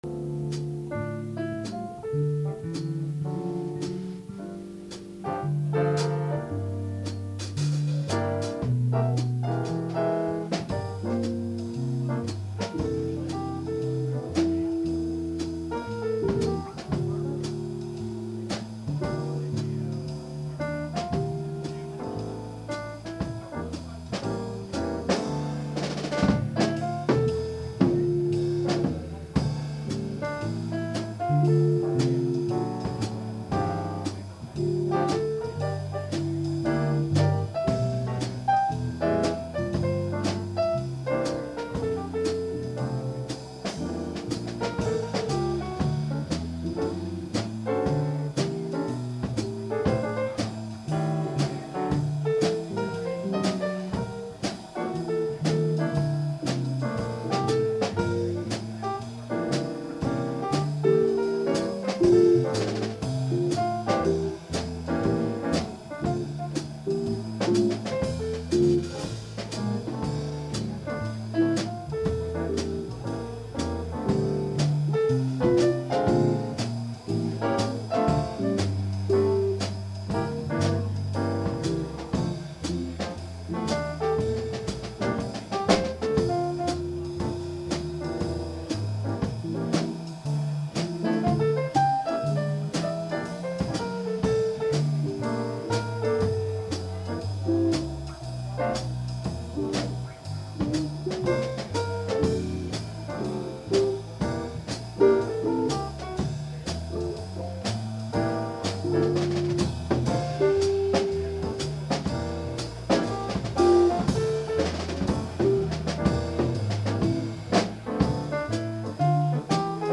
Jazz Recorded Live (unrehearsed)